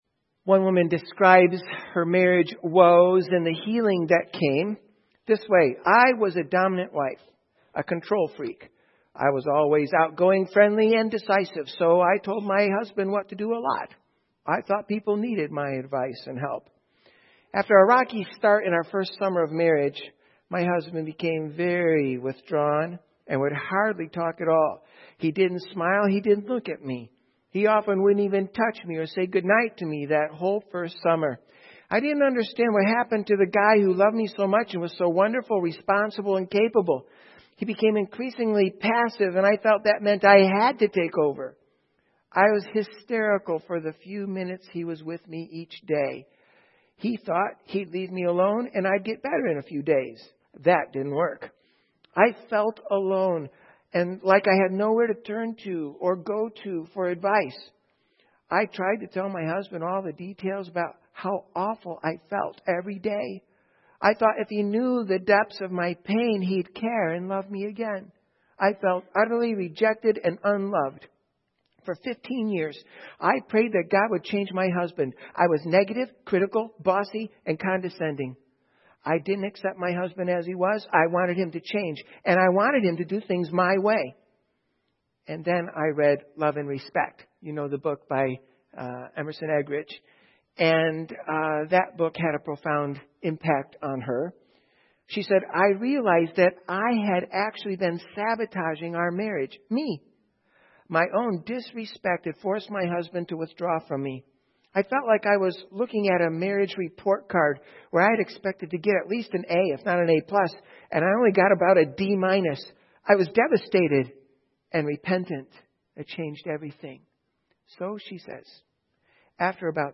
Audio Sermons